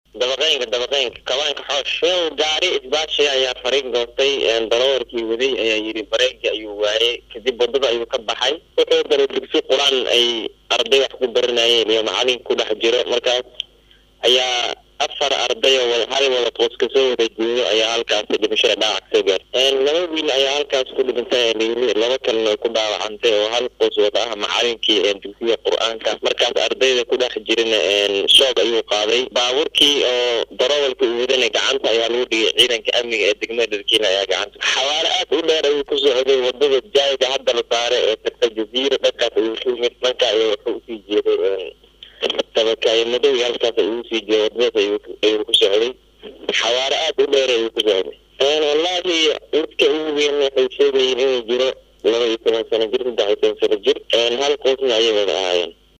Wareysi-qof-goobjooge.mp3